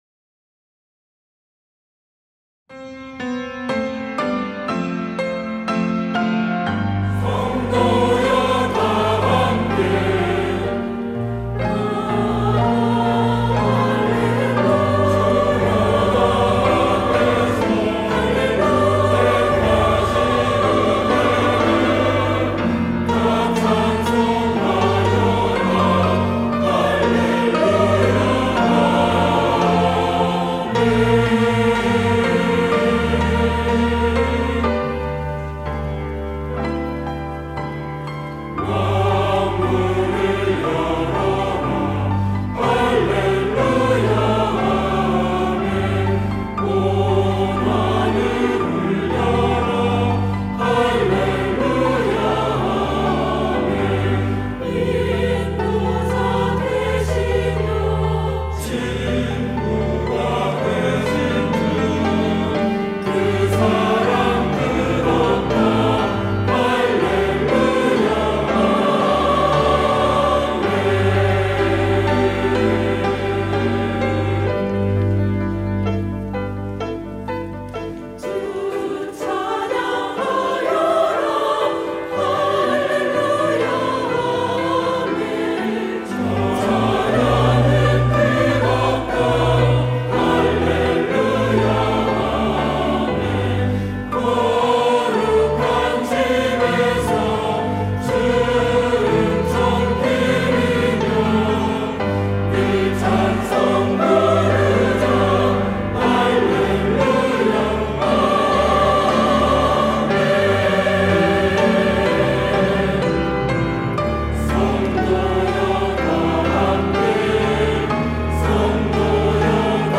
시온(주일1부) - 성도여 다함께
찬양대